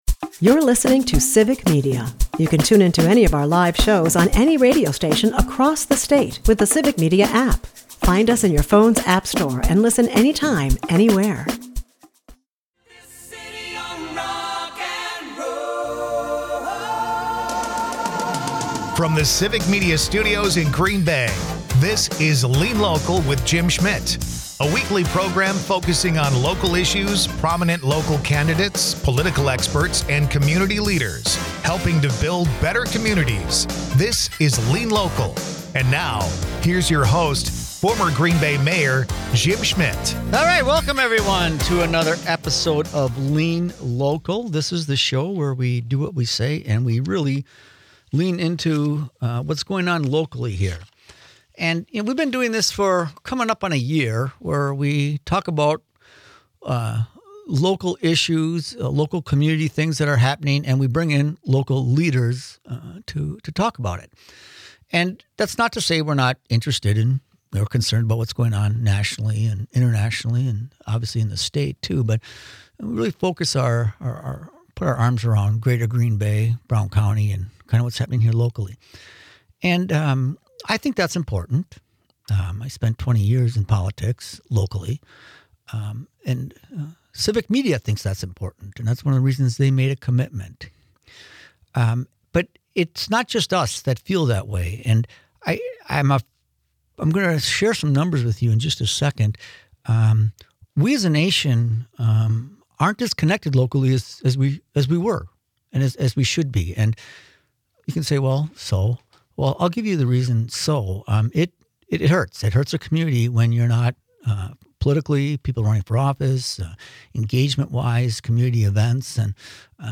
They unpack the rise of news deserts, the challenges local outlets face, and how rebuilding trust in neighborhood journalism could revive civic engagement in Northeast Wisconsin. A grounded conversation on why strong local reporting isn’t just good for readers.
Lean Local is a part of the Civic Media radio network and airs Sunday’s from 1-2 PM on WGBW .